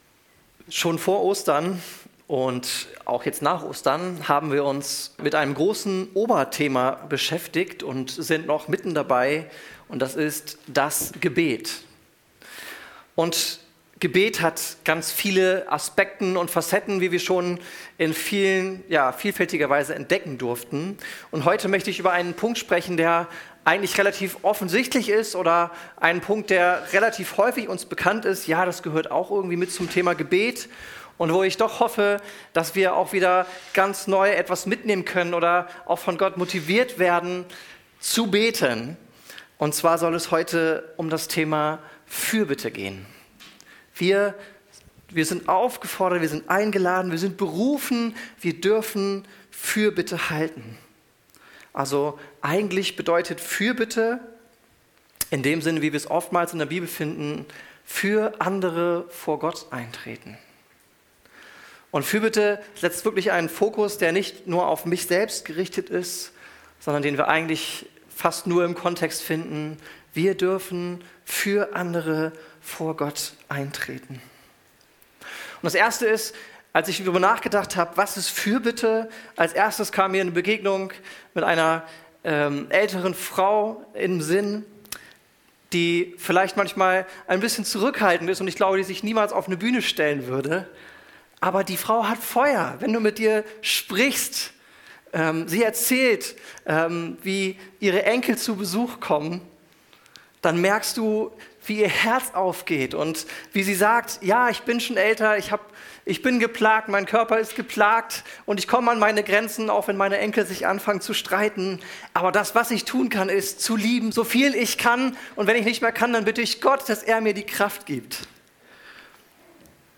Privileg und Chance Prediger